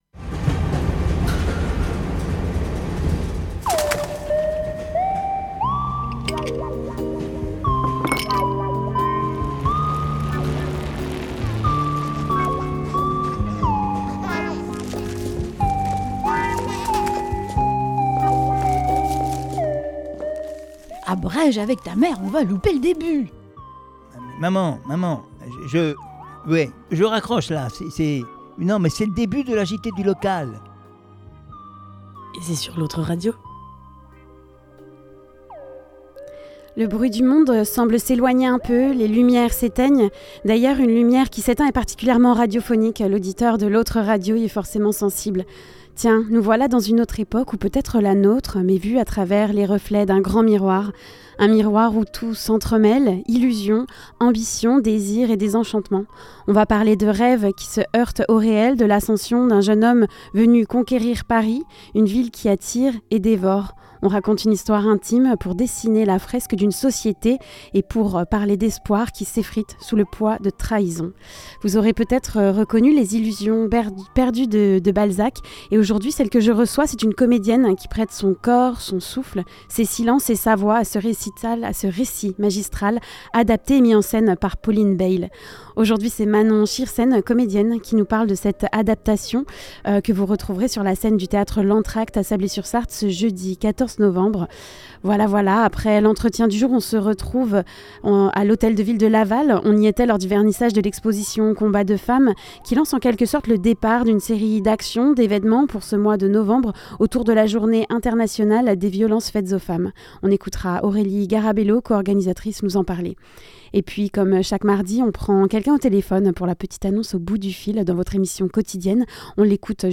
La petite annonce au Bout du Fil Avec le reportage du jour, on sort du studio ensemble pour se rendre au vernissage de l'exposition "Combats de femmes" visible dans trois lieux à Laval : l'hôtel de ville, le Centre Hospitalier et le restaurant Le Belvédère.